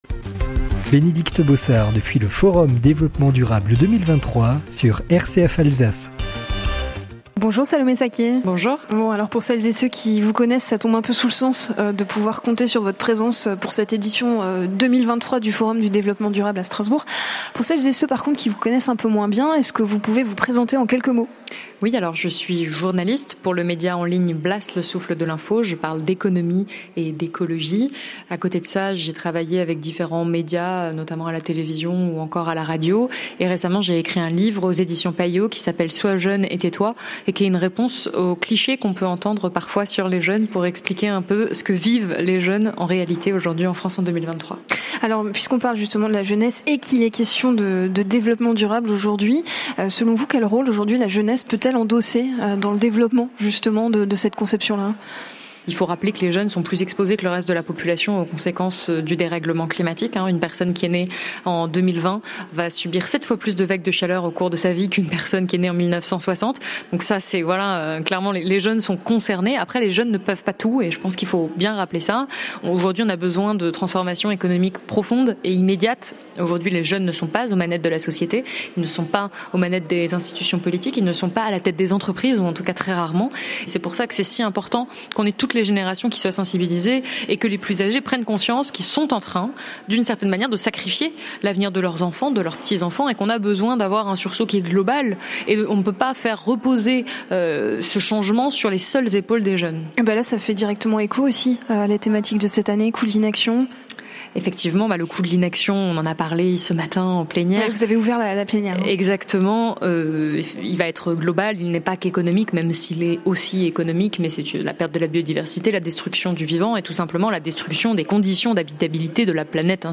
Nous en avons profité pour lui poser quelques questions au micro de RCF Alsace, partenaire du Forum dd et présent le jour J avec un plateau radio mobile.